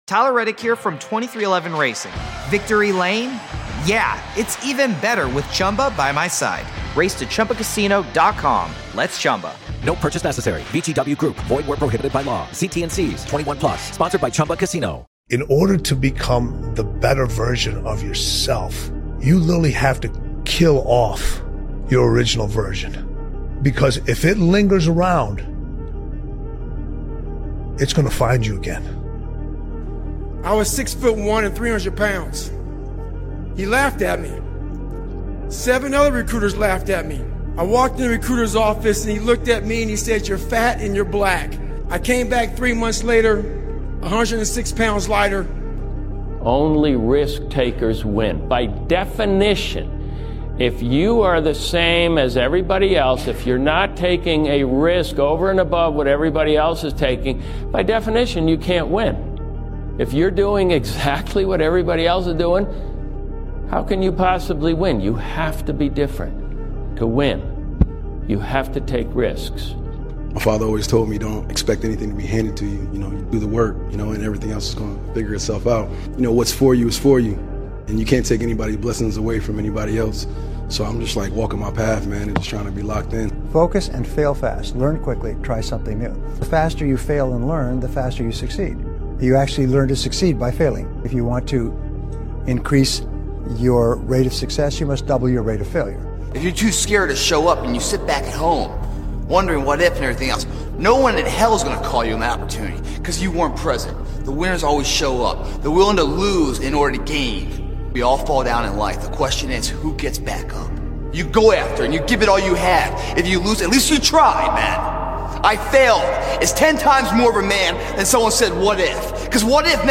Speaker: David goggins Joe RoganChris Williamson Alex Hormozi Connor Mcgregor Steve Harvey Jim Rohn Connor Mcgregor Denzel Washington Kevin Hart Will Smith Jocko WillinkMike Tyson Lebron James Elon Musk Brian Tracy Kobe Bryant